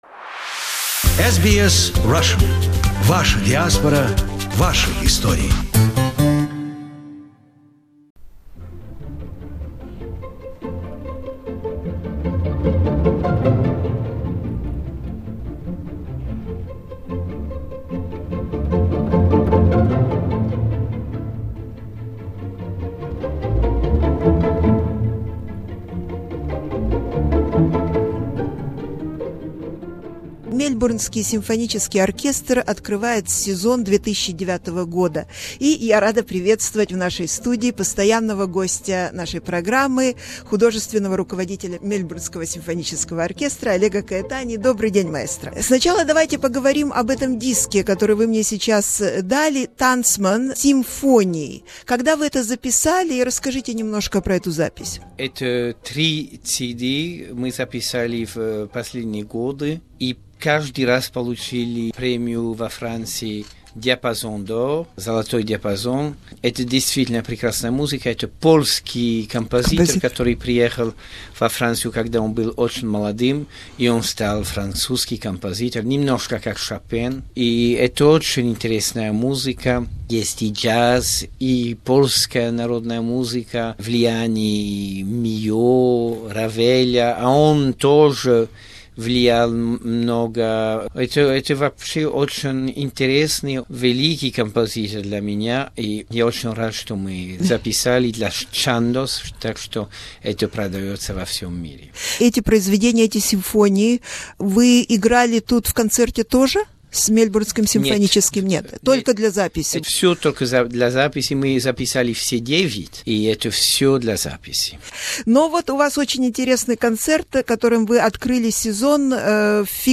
Musical conversations with maestro Oleg Caetani. Part 2